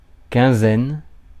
Ääntäminen
Synonyymit quinze jours Ääntäminen France: IPA: [kɛ̃.zɛn] Haettu sana löytyi näillä lähdekielillä: ranska Käännös Substantiivit 1. петнадесетина Suku: f .